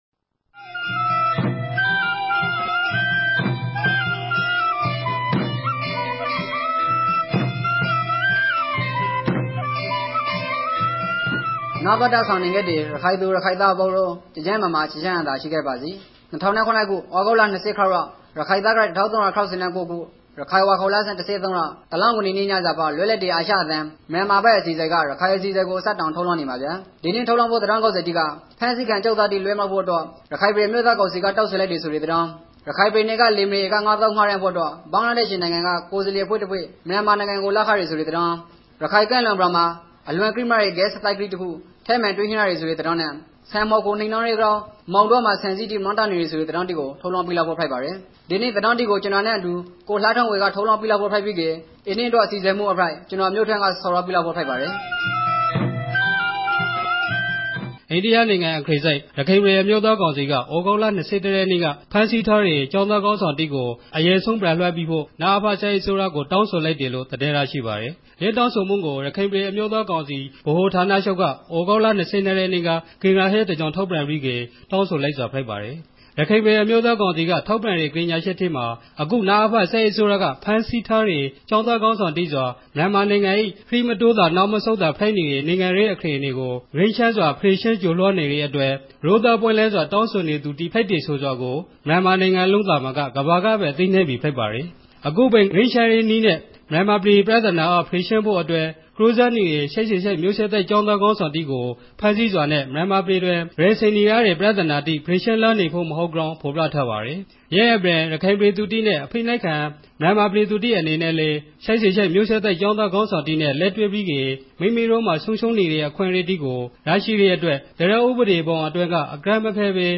ရခိုင်ဘာသာအသံလြင့်အစီအစဉ်မဵား